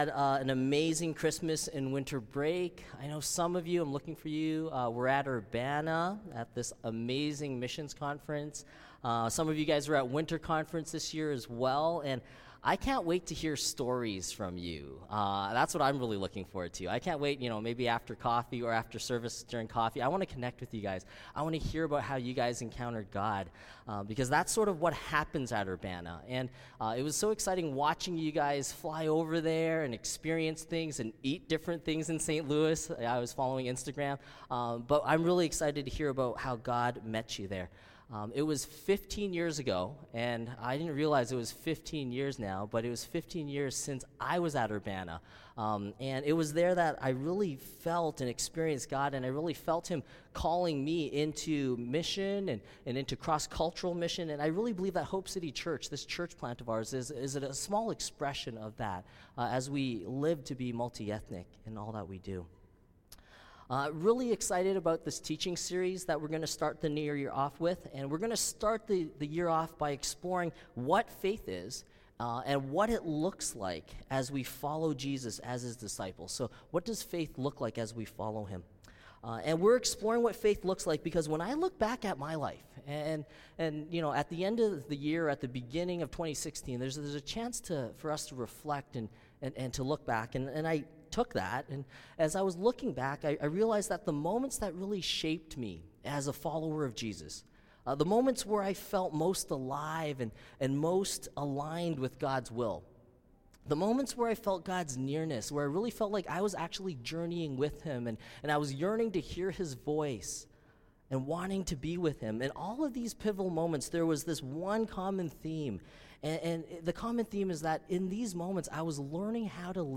Audio-Sermon-Jan-3-2016-Living-by-Faith.mp3